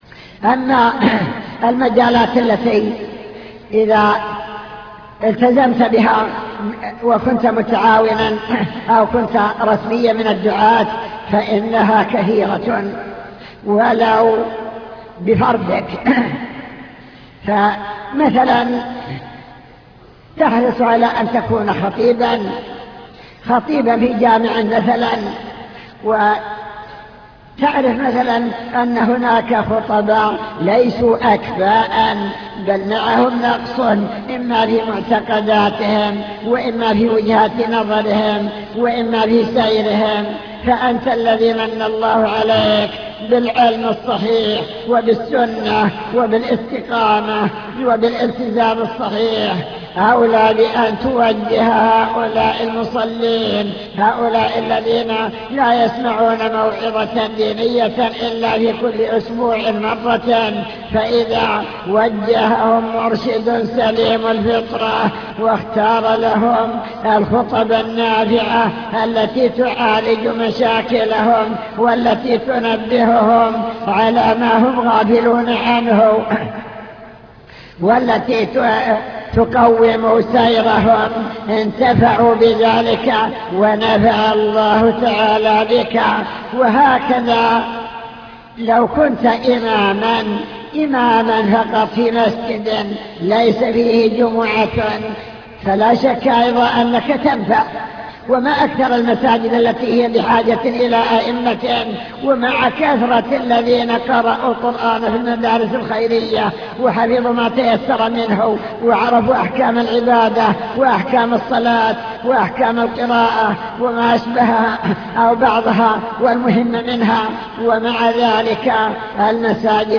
المكتبة الصوتية  تسجيلات - محاضرات ودروس  محاضرة في حقيقة الالتزام